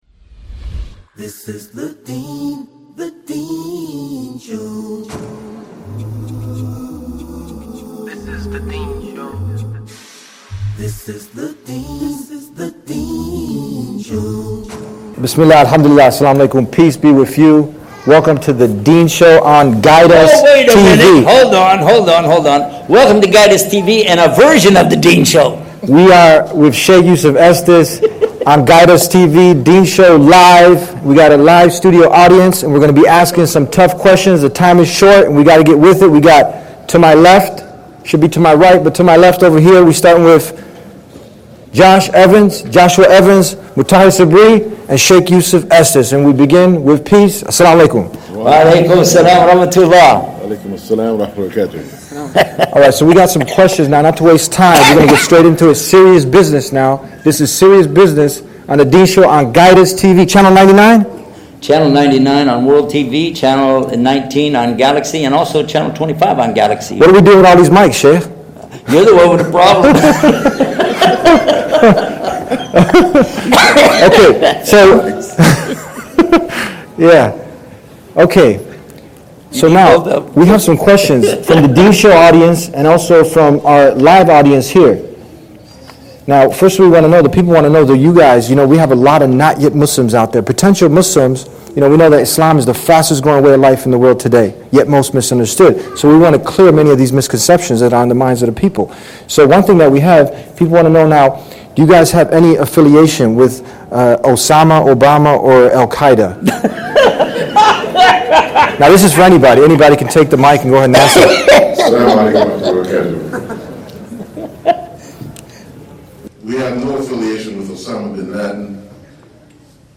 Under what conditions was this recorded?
Together, they tackle tough questions from both the studio audience and The Deen Show viewers, addressing common misconceptions about Islam, terrorism, and what it truly means to be a Muslim in America.